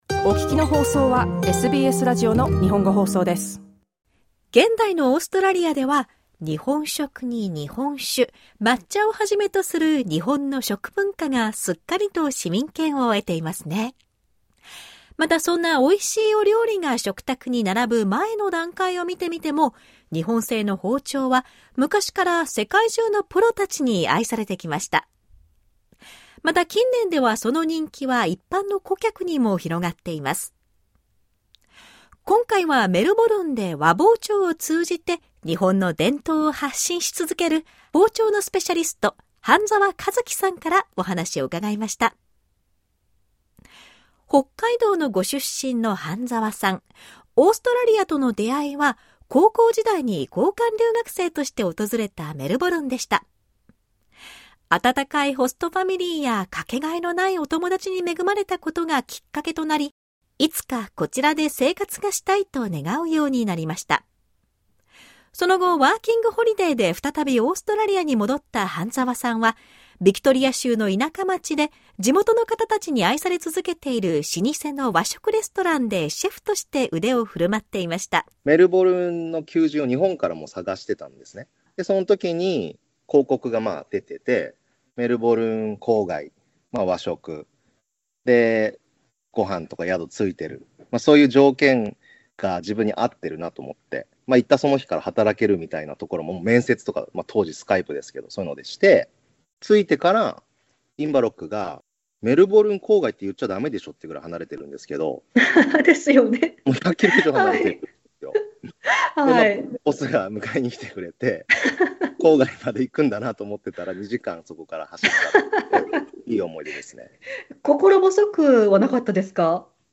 In this interview, he shares why these knives are so beloved both in and outside of Japan, and the memorable moments with local professionals and home chefs these knives have brought to his life over the years.